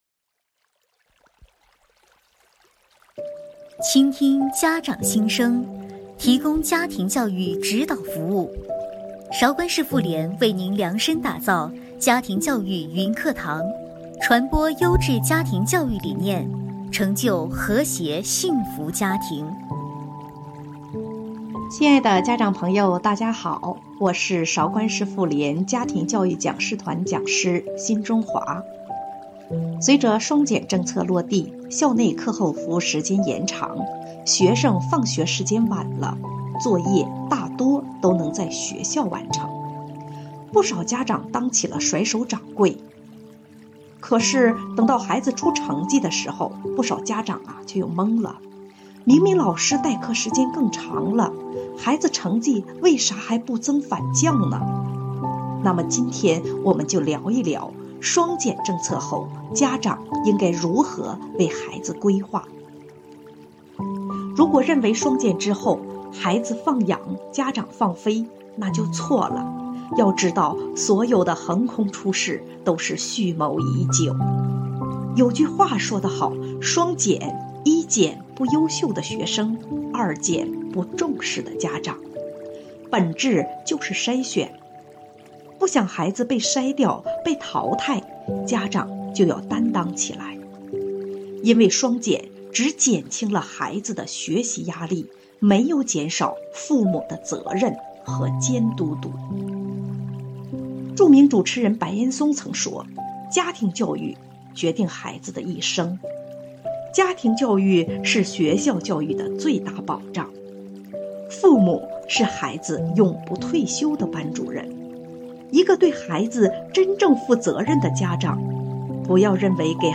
主讲人：中国家校合作教育指导师